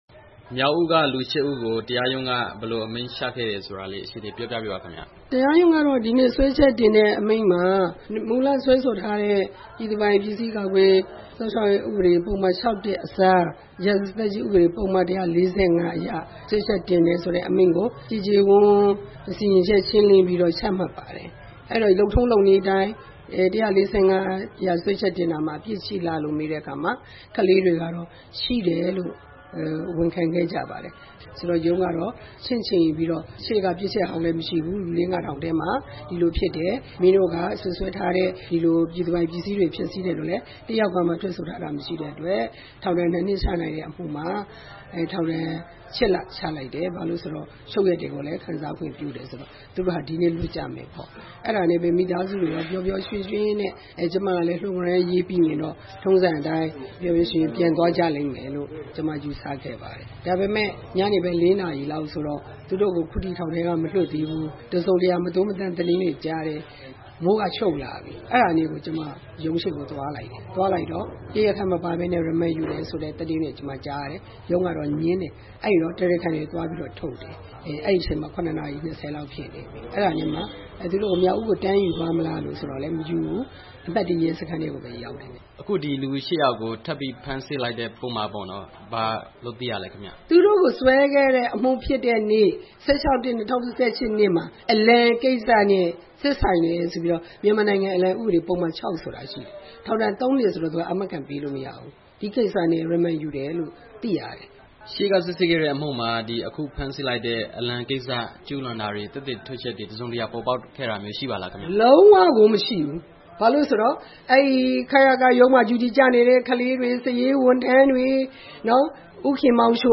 တွေ့ဆုံ မေးမြန်းချက်